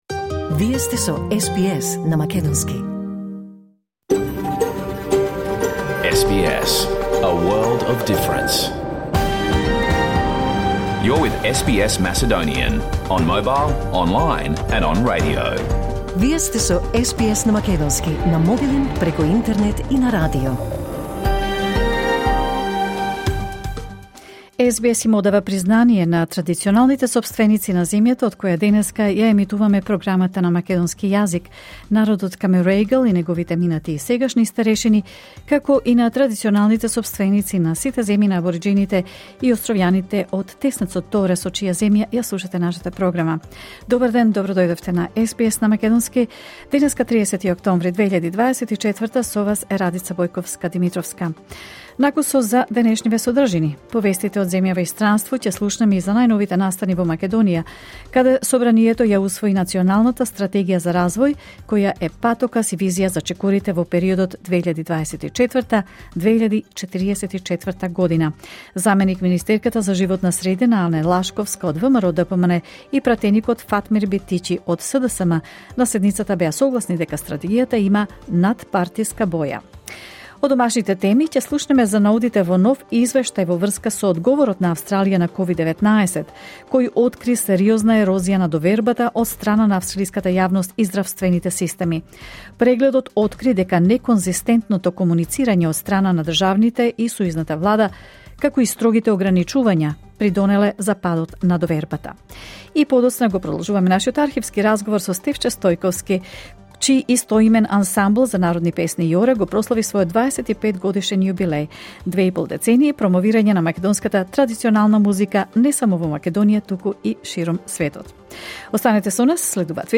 SBS Macedonian Program Live on Air 30 October 2024